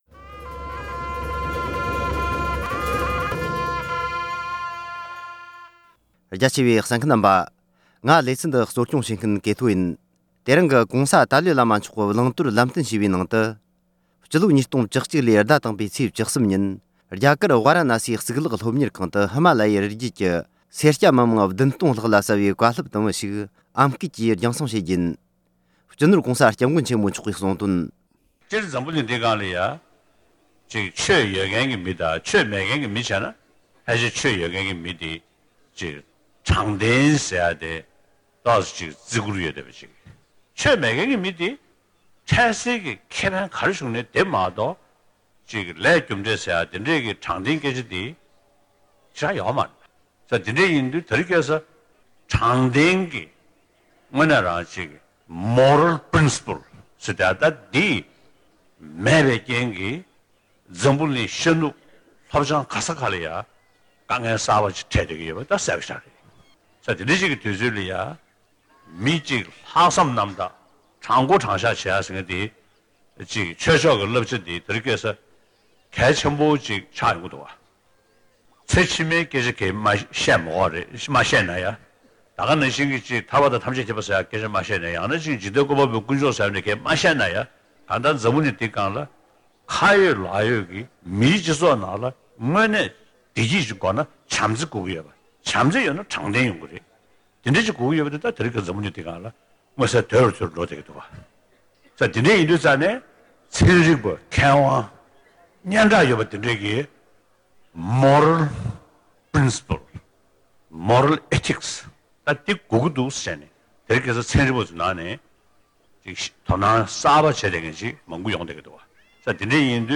ཨམ་སྐད་དུ་ཕྱོགས་སྒྲིག་དང་སྙན་སྒྲོན་ཞུས་པར་གསན་རོགས་ཞུ༎